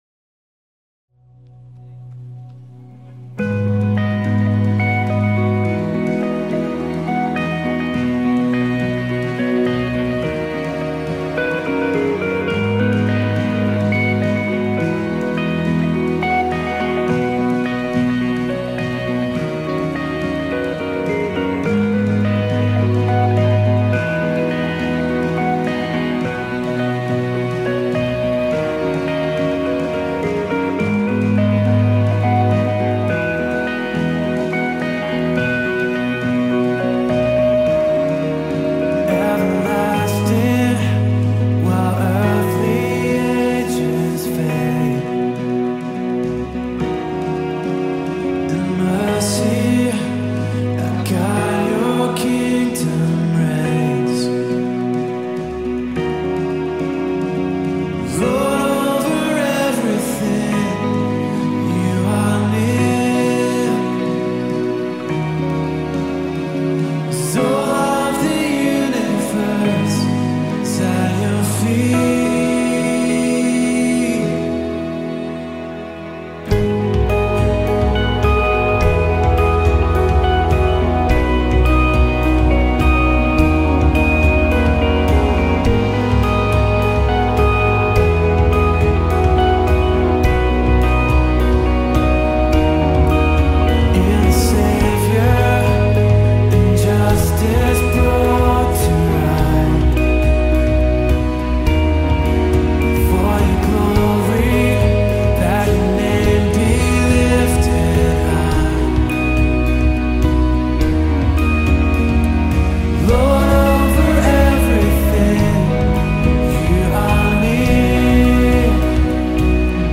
5841 просмотр 1343 прослушивания 340 скачиваний BPM: 105